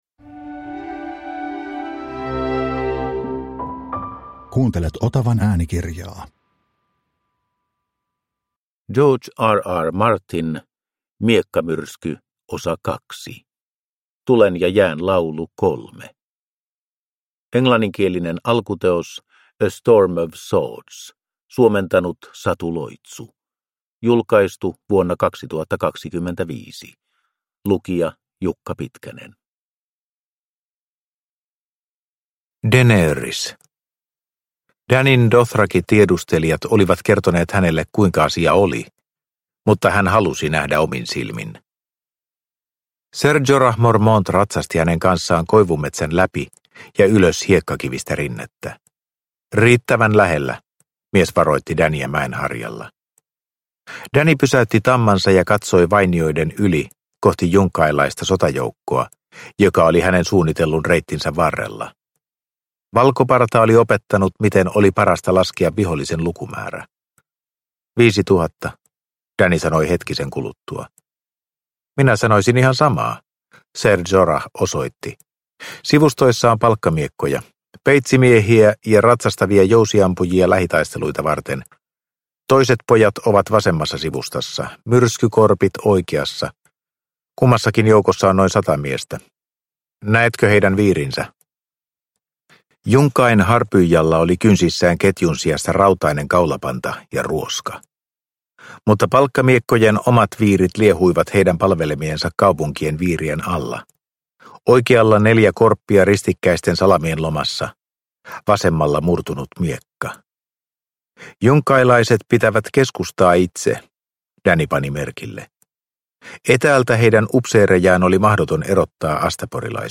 Miekkamyrsky 2 – Ljudbok